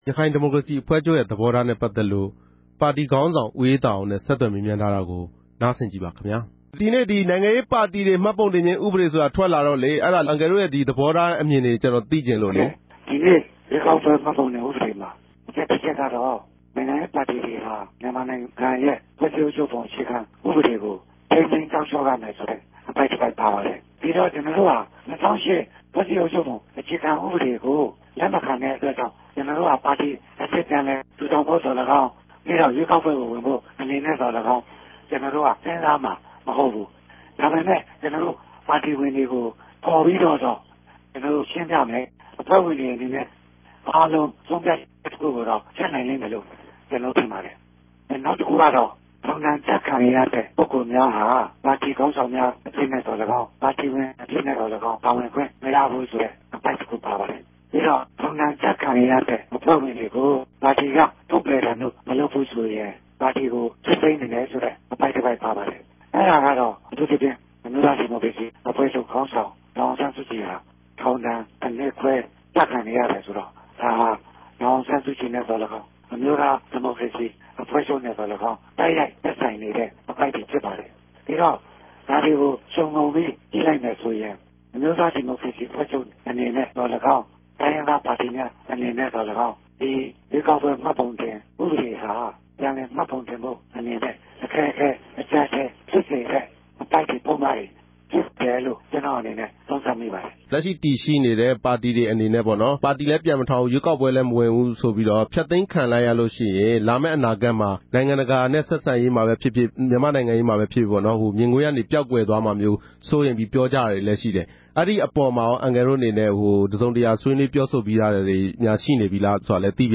ဆက်သြယ် မေးူမန်းတင်ူပထားပၝတယ်။